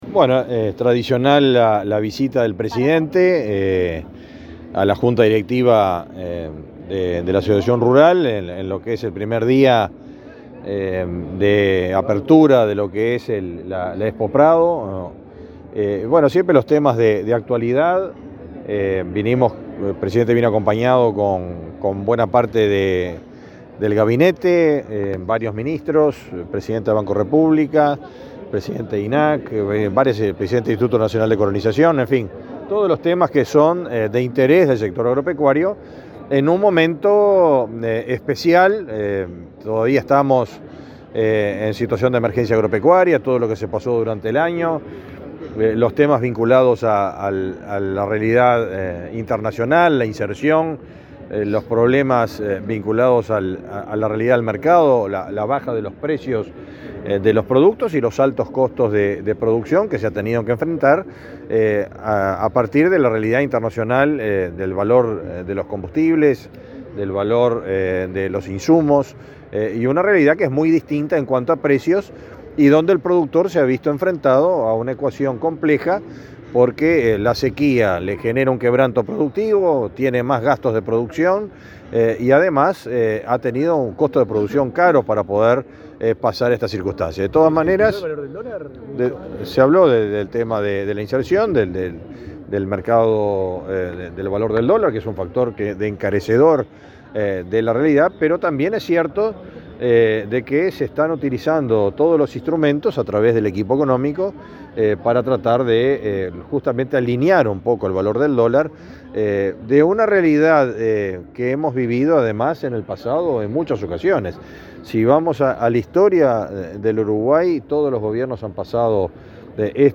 Declaraciones del ministro de Ganadería, Fernando Mattos
Declaraciones del ministro de Ganadería, Fernando Mattos 08/09/2023 Compartir Facebook X Copiar enlace WhatsApp LinkedIn El ministro de Ganadería, Fernando Mattos, dialogó con la prensa, luego de participar en un desayuno con representantes de la Asociación Rural del Uruguay en la Expo Prado.